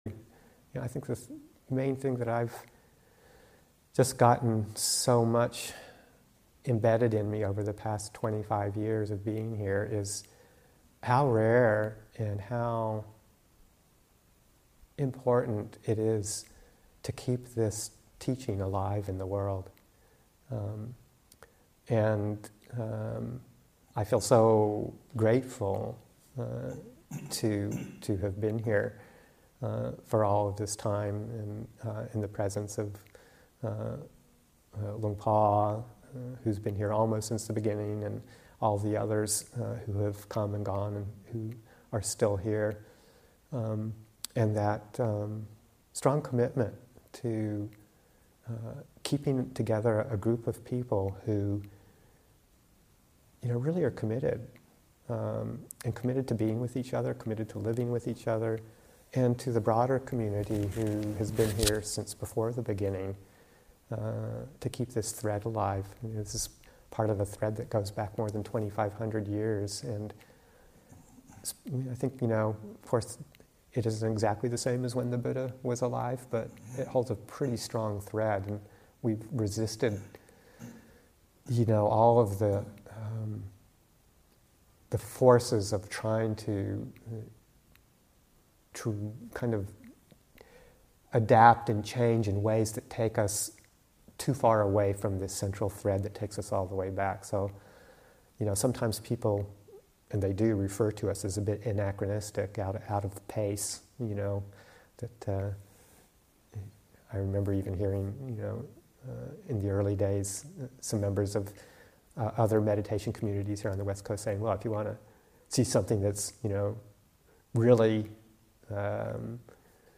Abhayagiri 25th Anniversary Retreat [2021], Session 18, Excerpt 16